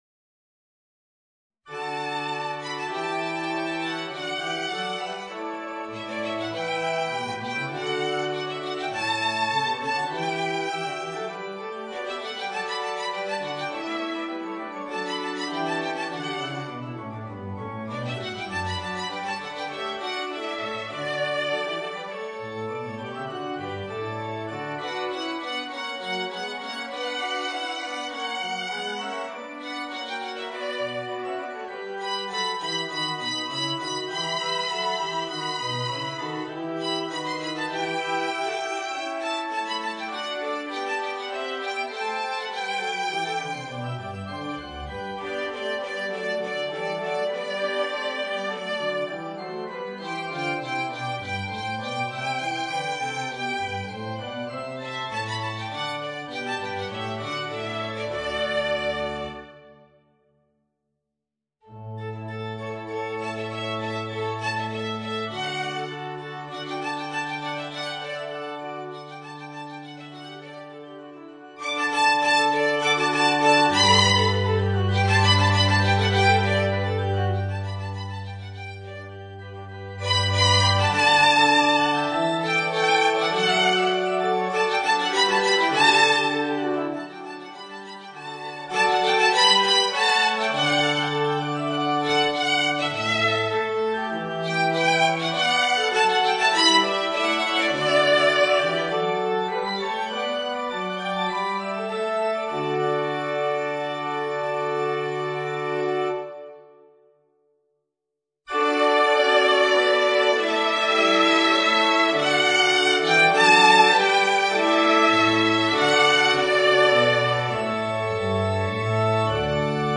Voicing: Violin and Organ